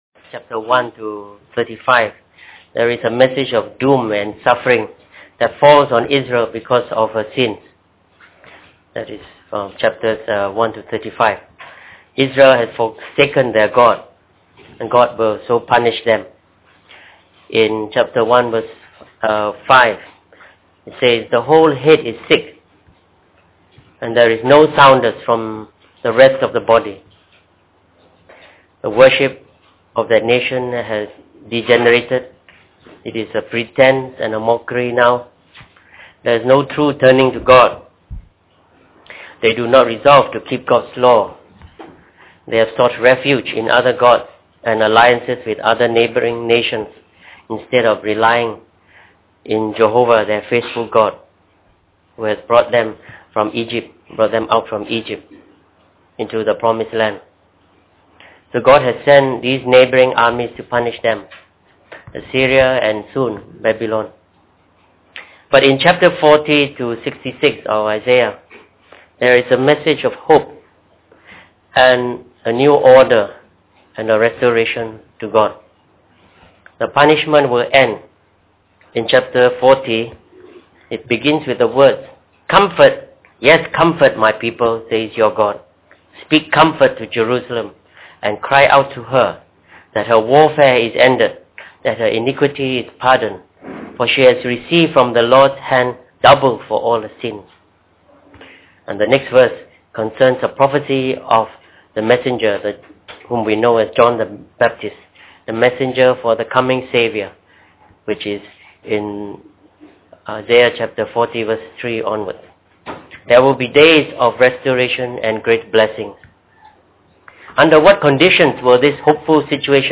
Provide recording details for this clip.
Preached on the 27th of April 2008.